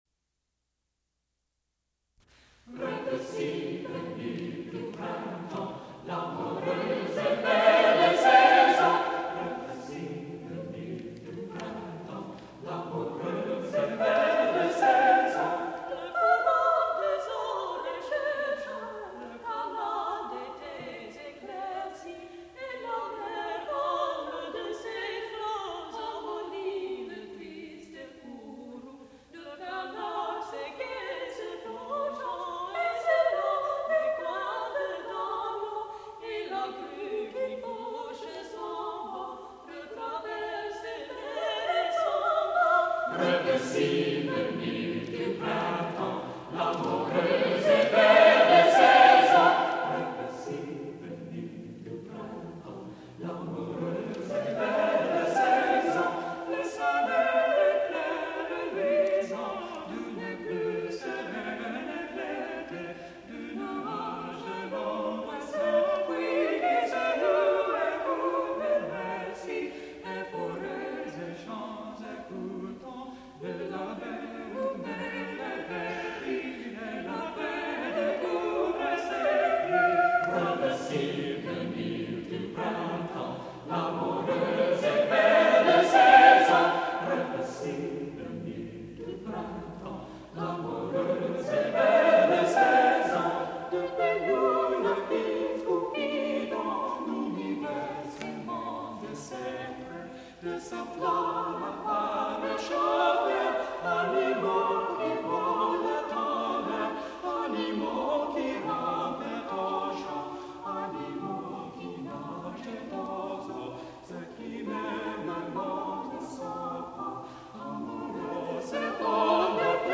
Which consists of applying longer note durations to accented syllables in the text resulting in irregular meters.
. surprising since the optimum tempo requires singers of exceptional virtuosity.